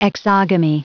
Prononciation du mot exogamy en anglais (fichier audio)
Prononciation du mot : exogamy